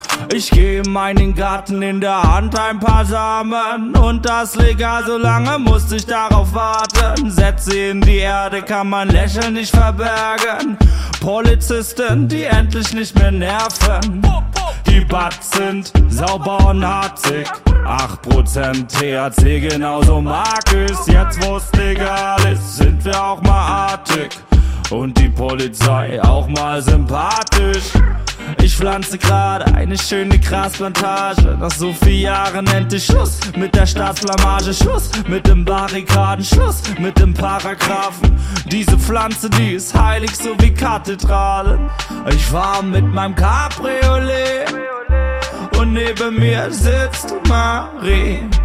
Catégorie Rap